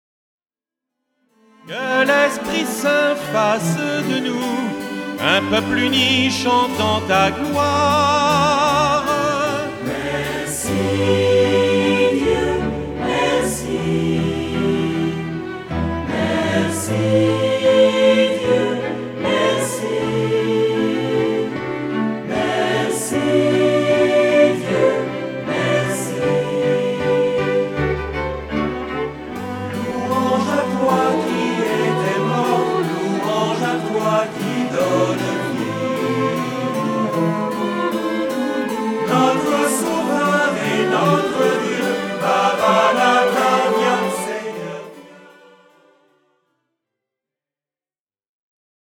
) sont servis par des instruments à cordes et piano.